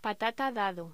Locución: Patata dado
voz
Sonidos: Hostelería